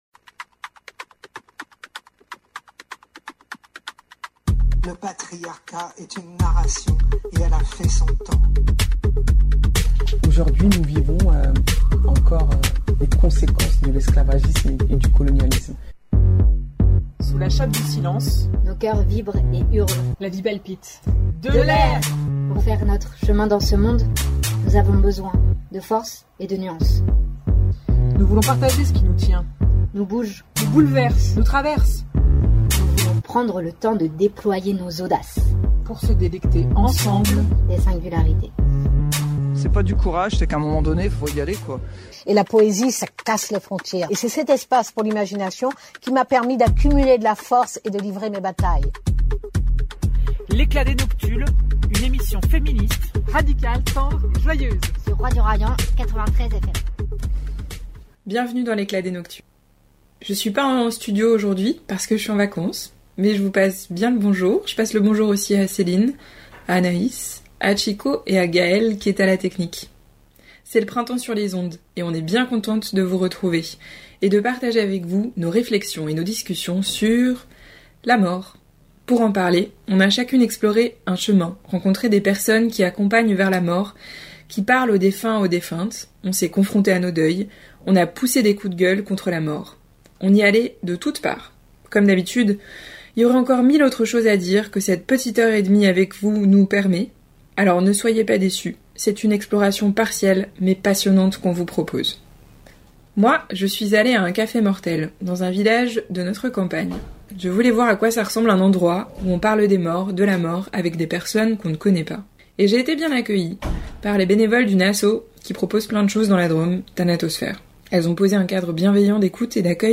Entre interview, chansons, textes intimes et coups de gueule, on vous murmure des réalités qui nous touchent.